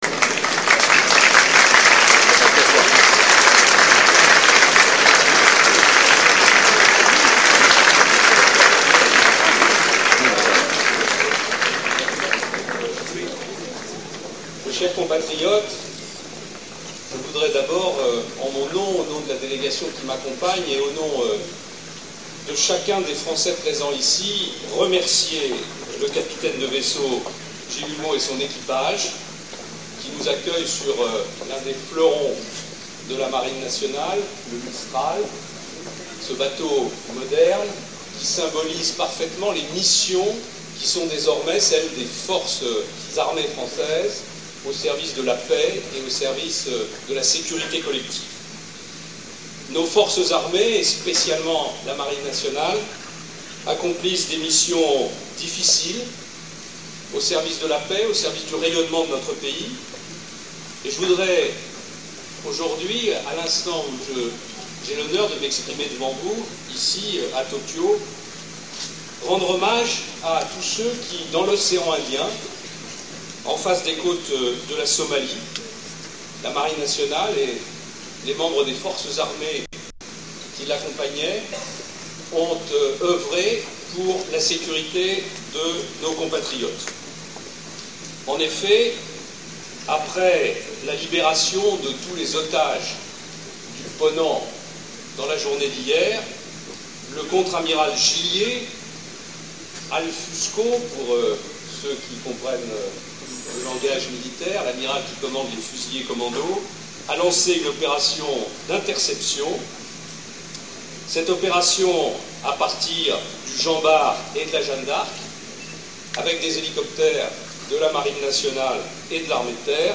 discoursfillionmistral.mp3